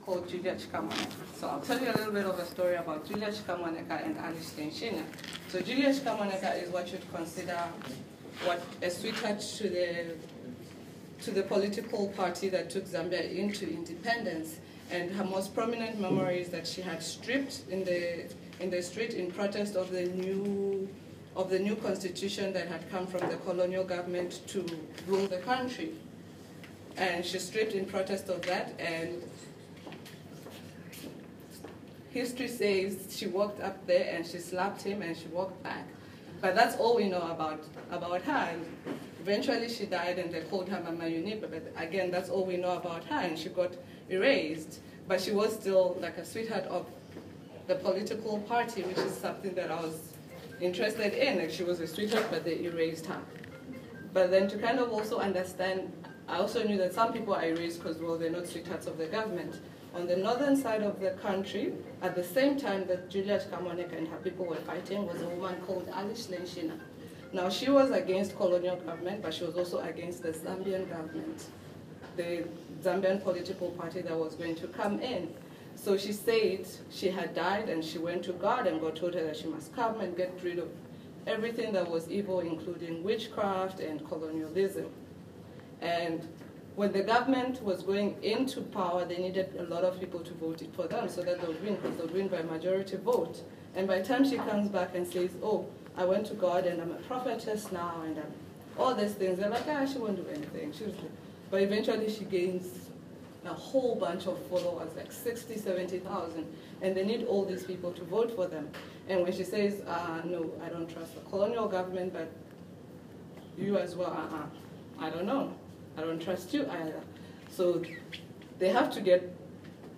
Hear the artist explain her art:
The artist explains her art to us.